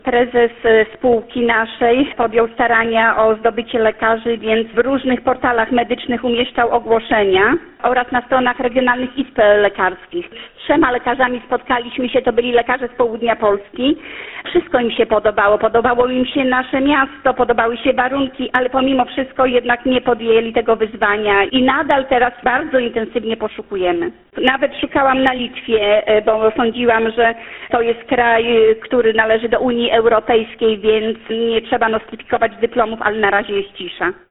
– Sześciu lekarzy kontaktowało się z naszą spółką, natomiast dwóch to byli lekarze z Białorusi, którzy nie mają nostryfikowanych dyplomów – mówi starosta powiatu gołdapskiego Marzanna Wardziejewska.